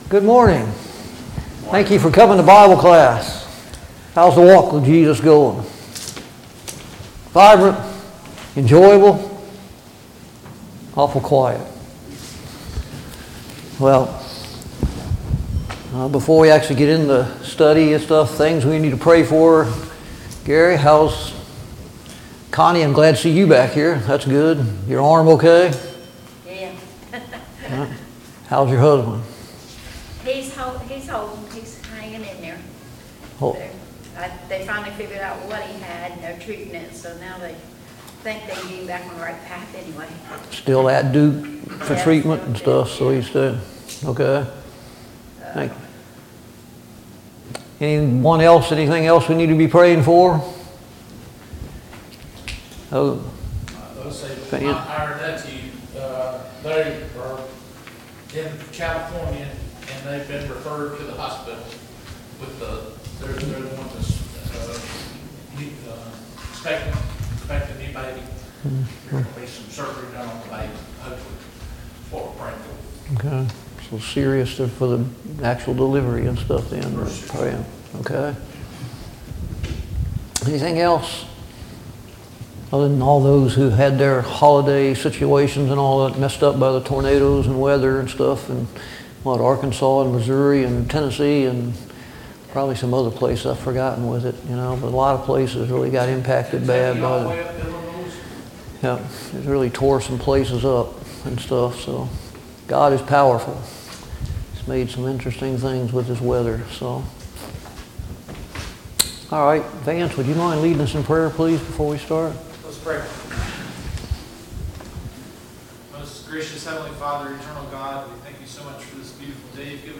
Study on the Minor Prophets Passage: Micah 6 Service Type: Sunday Morning Bible Class « 14.